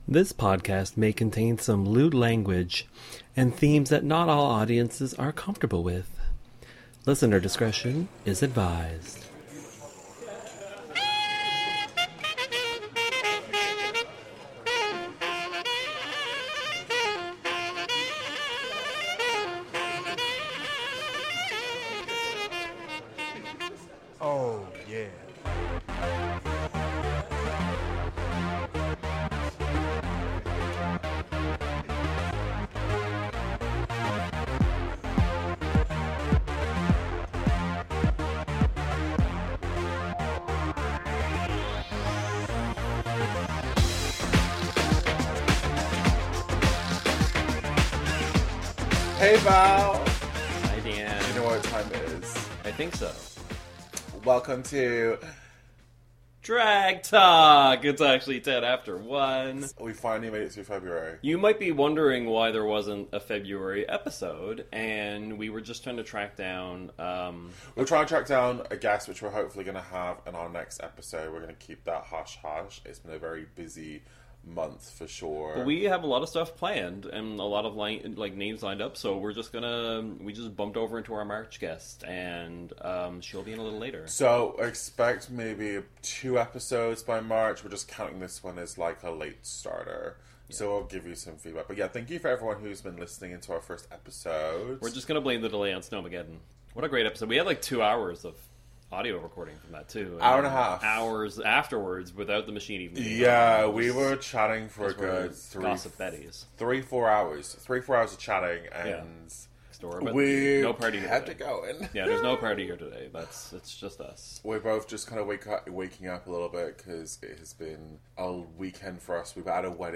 Drag Talk is a interview showcase of drag performers and professionals living and werking in St John's Newfoundland and Labrador, Canada.